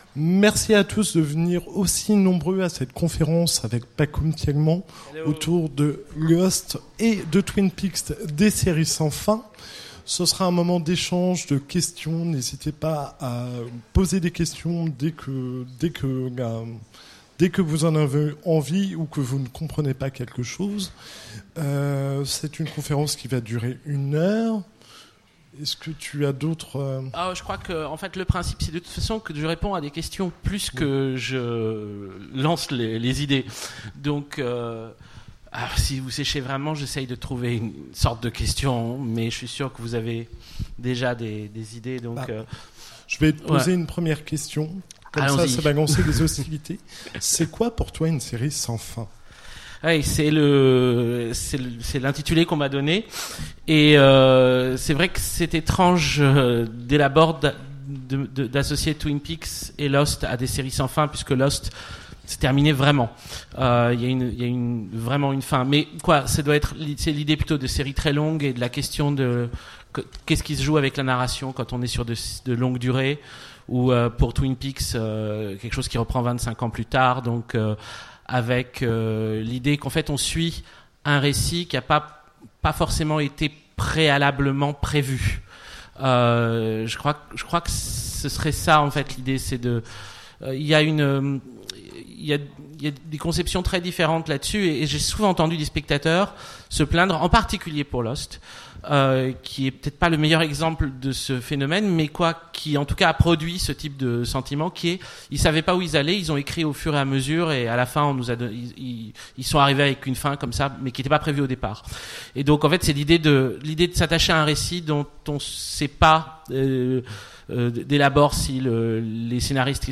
Utopiales 2017 : Conférence Lost in Twin Peaks : séries sans fin